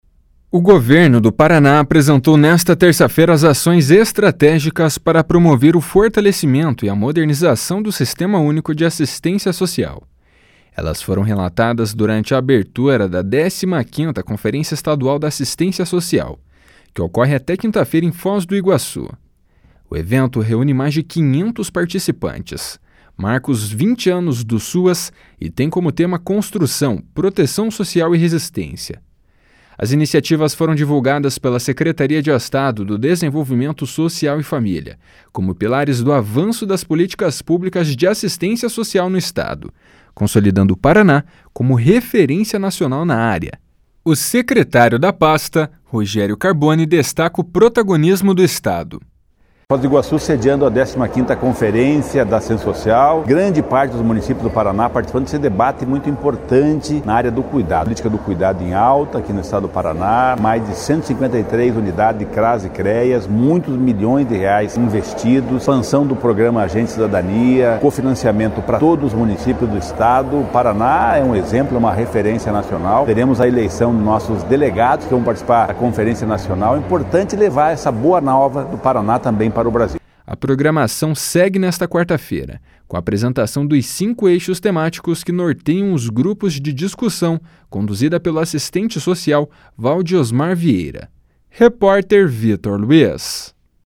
O secretário da pasta, Rogério Carboni, destaca o protagonismo do Estado. // SONORA ROGÉRIO CARBONI //